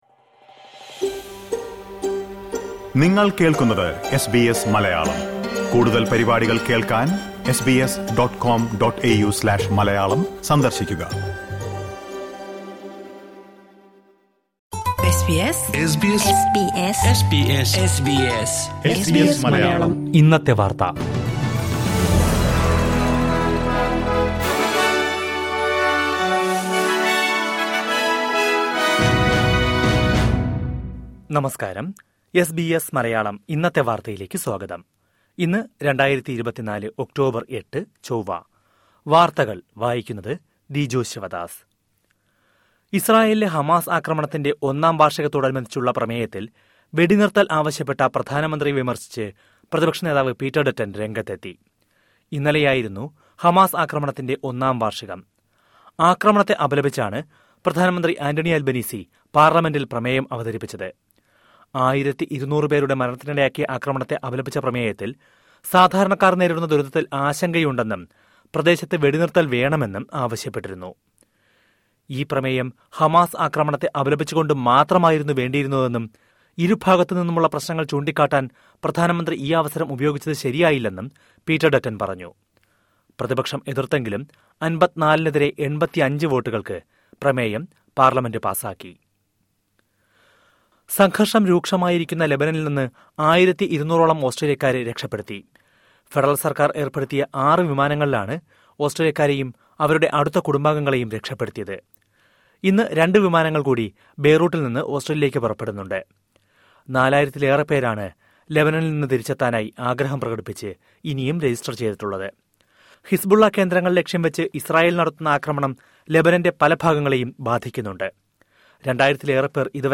2024 ഒക്ടോബര്‍ എട്ടിലെ ഓസ്‌ട്രേലിയിലെ ഏറ്റവും പ്രധാന വാര്‍ത്തകള്‍ കേള്‍ക്കാം...